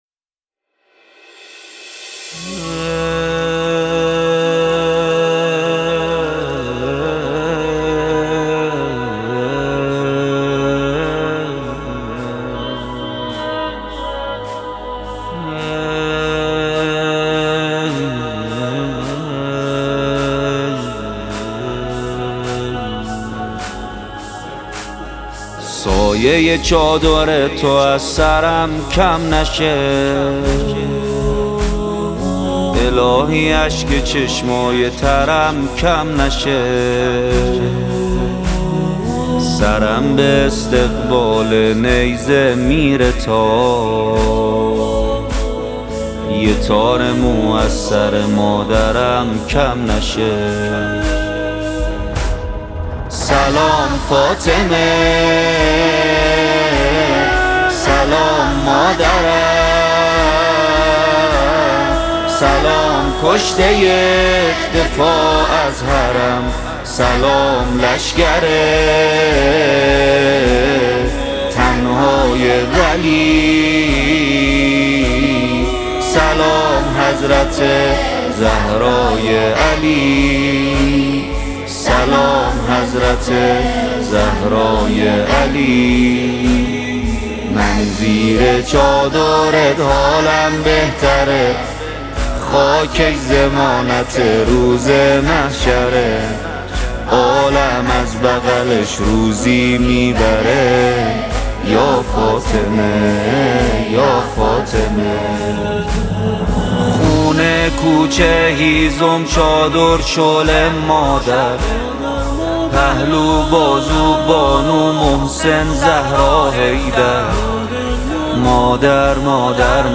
• دانلود نوحه و مداحی